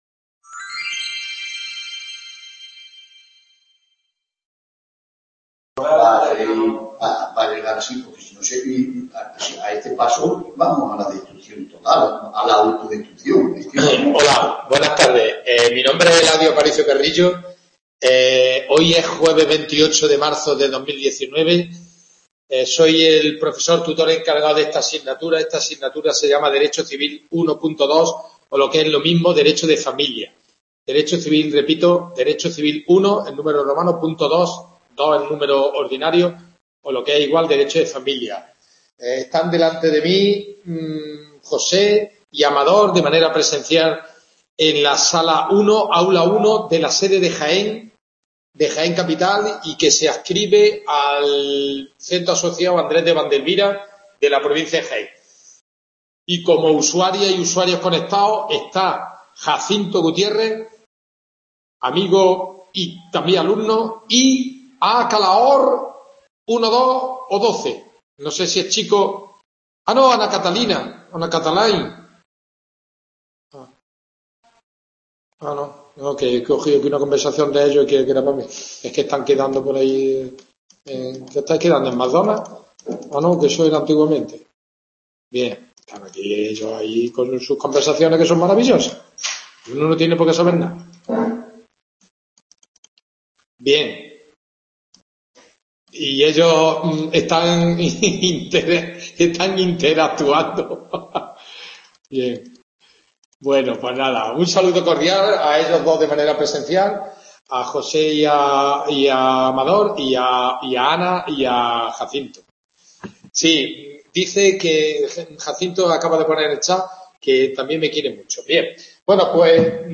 WEBCONFERENCIA